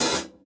Open Hats
Metro OH1.wav